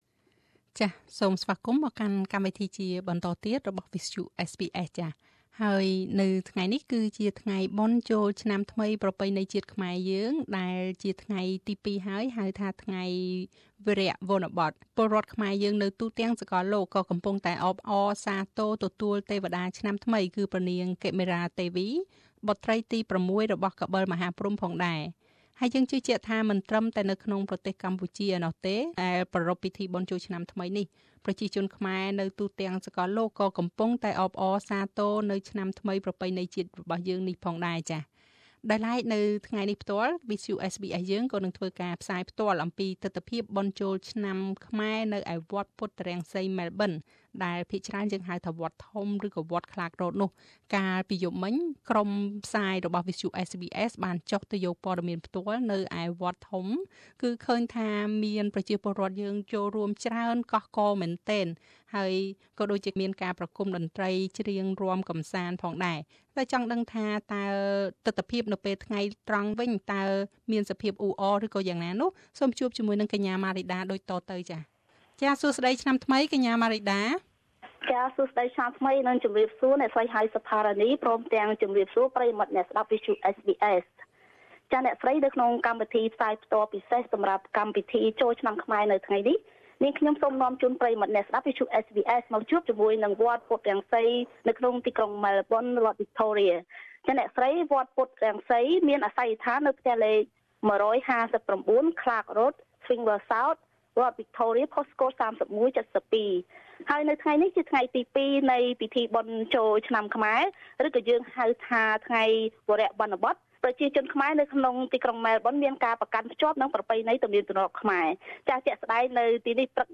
ការផ្សាយបន្តផ្ទាល់ពីពិធីបុណ្យចូលឆ្នាំថ្មីប្រពៃណីជាតិខ្មែរនៅវត្តពុទ្ធរង្សីម៉ែលប៊ន នាថ្ងៃទី វារៈវនបត ត្រូវនឹងថ្ងៃទី១៥មេសាឆ្នាំ២០១៧។ សូមជូនពរឆ្នាំថ្មី បវរសួស្តី ជ័យមង្គល មានដល់ប្រជាពលរដ្ឋខ្មែរគ្រប់រូប៕